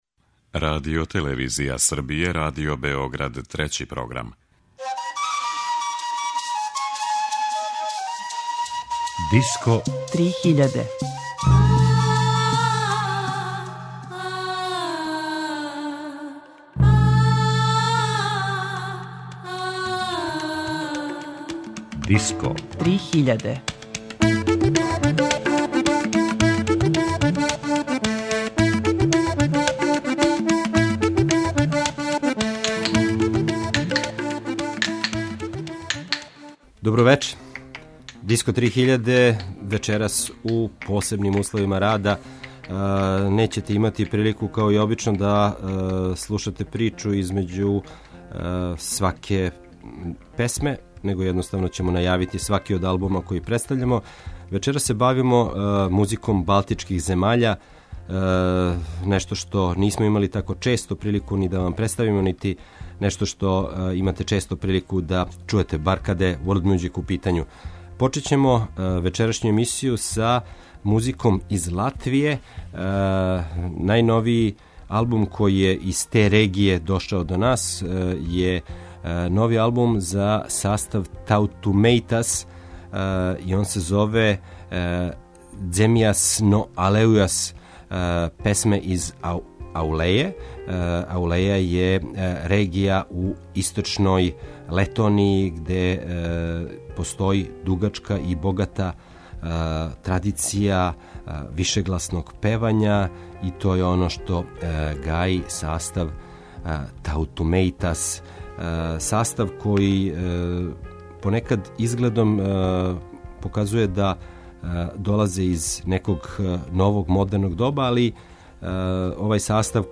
Музика балтичких земаља
world music